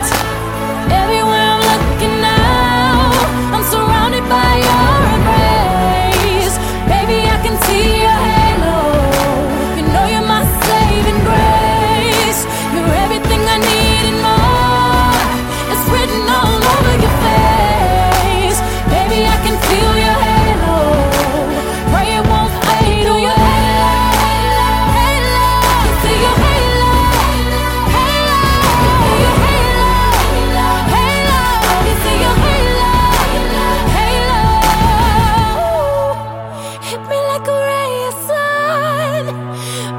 красивый женский голос
rnb , поп